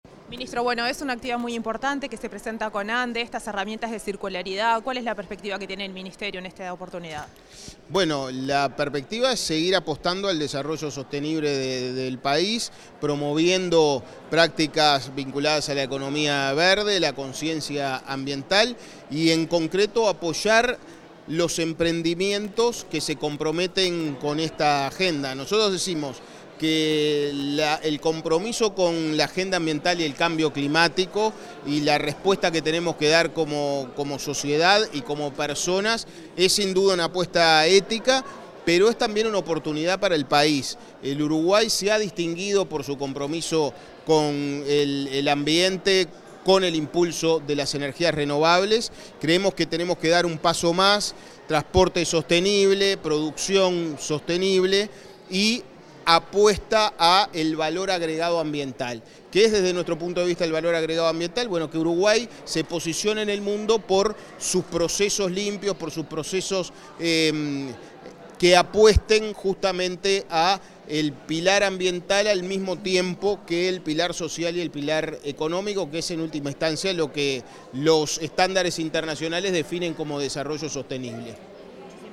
Declaraciones del ministro de Ambiente, Edgardo Ortuño
El ministro de Ambiente, Edgardo Ortuño, dialogó con la prensa tras participar en el evento Promoviendo la Economía Circular: Presentación del Portal